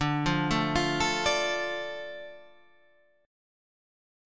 D Minor